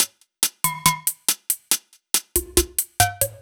Index of /musicradar/french-house-chillout-samples/140bpm/Beats
FHC_BeatD_140-02_Tops.wav